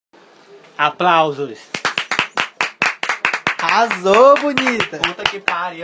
Play Aplausos! Arrasou Bonita! - SoundBoardGuy
aplausos-arrasou-bonita.mp3